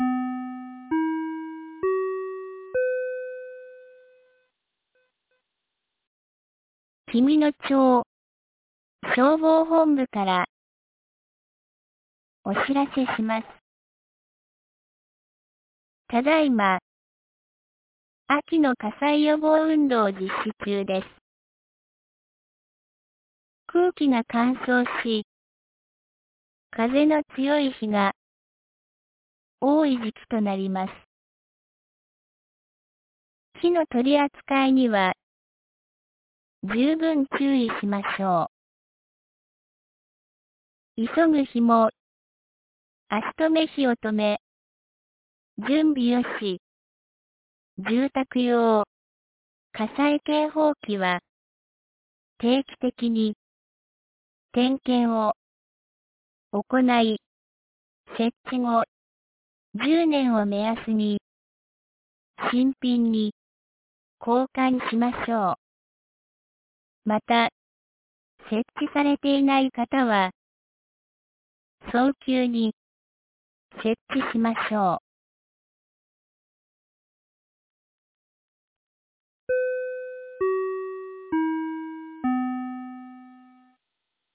2025年11月11日 17時06分に、紀美野町より全地区へ放送がありました。